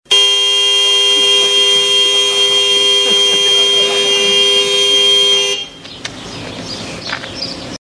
LOUD HORN BLARING.wav
LOUD_HORN_BLARING_jXr.wav